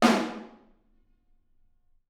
R_B Snare 02 - Room.wav